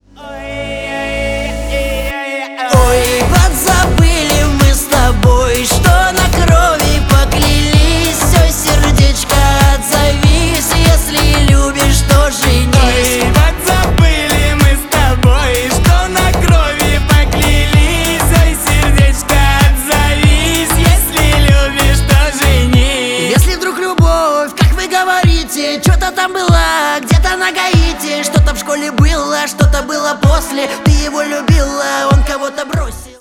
громкие
поп